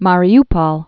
(märē-pŏl)